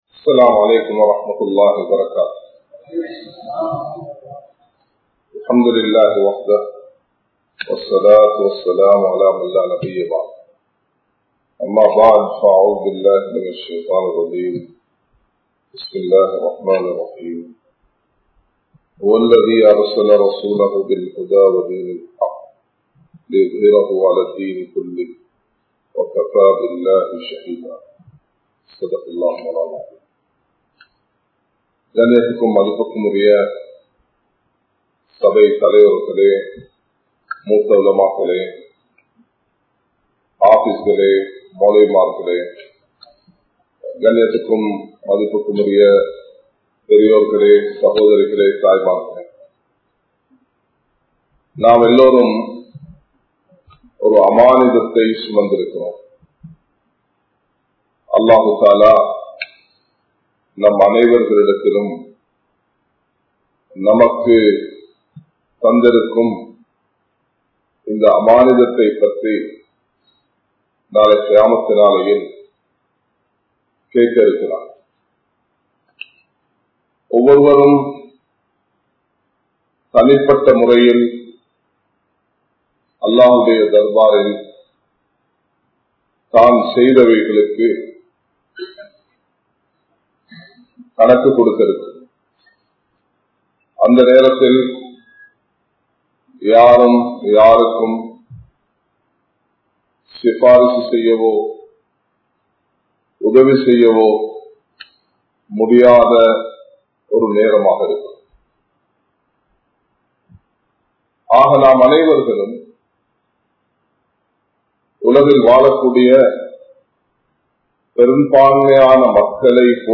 Thatkaalaththil Aalimkalin Kadamai (தற்காலத்தில் ஆலிம்களின் கடமை) | Audio Bayans | All Ceylon Muslim Youth Community | Addalaichenai
Colombo 03, Memon Hall